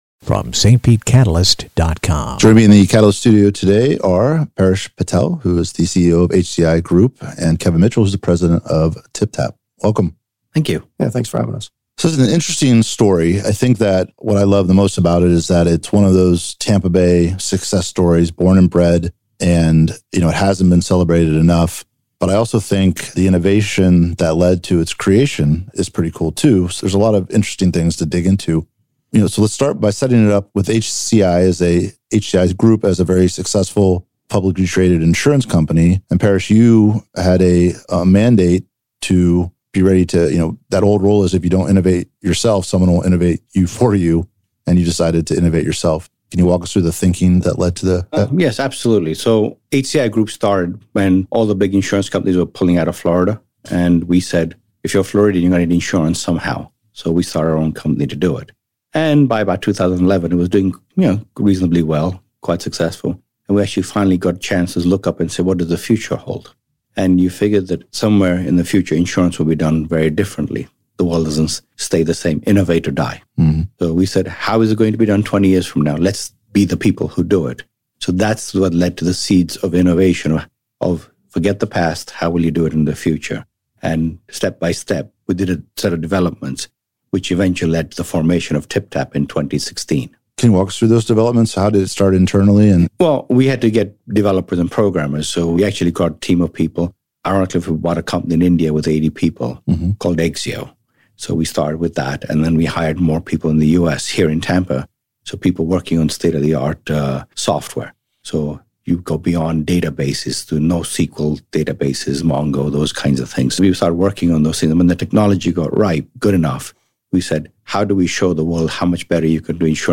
St. Pete Catalyst Interview w